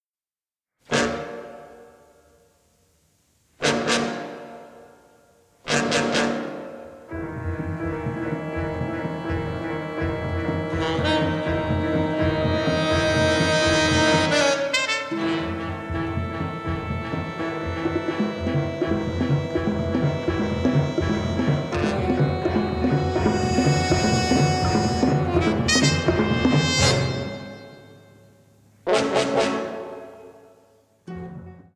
jazz-rooted score